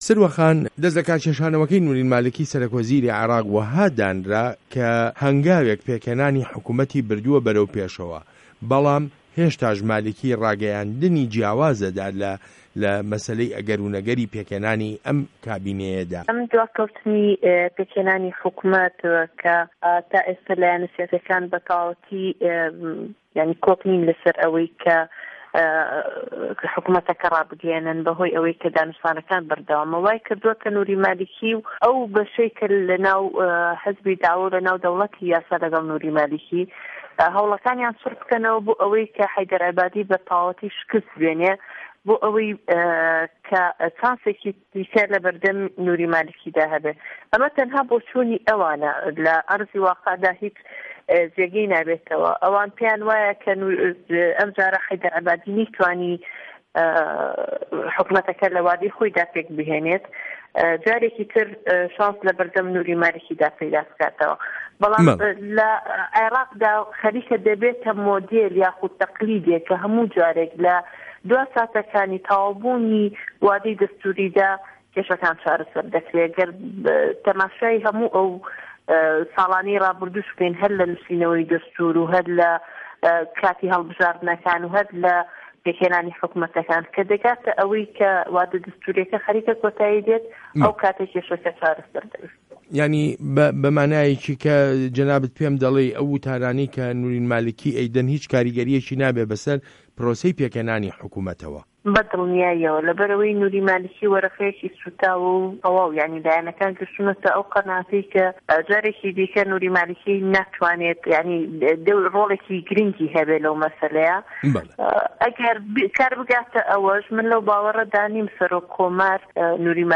وتووێژ له‌گه‌ڵ سروه‌ عه‌بدولواحید